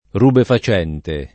vai all'elenco alfabetico delle voci ingrandisci il carattere 100% rimpicciolisci il carattere stampa invia tramite posta elettronica codividi su Facebook rubefacente [ rubefa ©$ nte ] (alla lat. rubefaciente [ id.